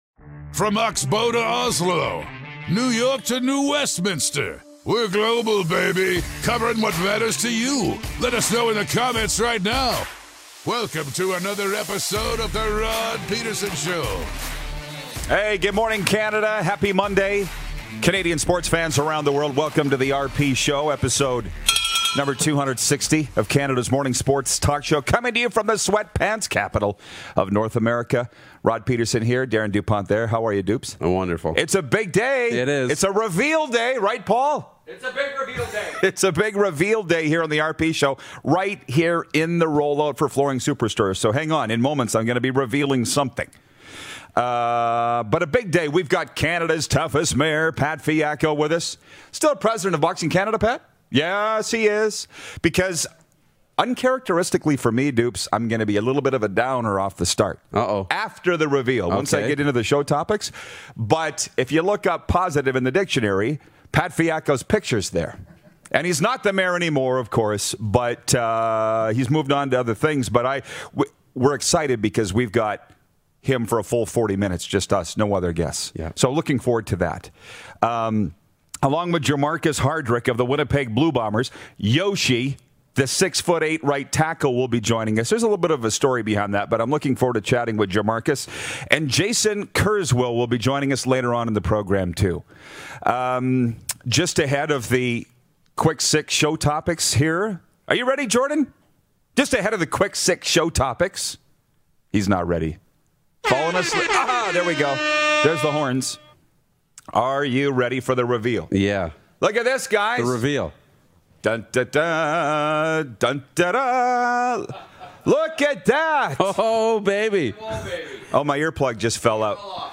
Canada’s Toughest (former) Mayor, Pat Fiacco joins us in studio for Hour 1 with lots to talk about!